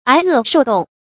挨饿受冻 ái è shòu dòng
挨饿受冻发音